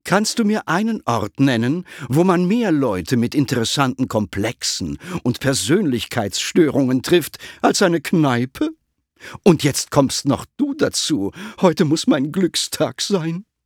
Beschreibung: Sprachbeispiel des Geisterpsychiaters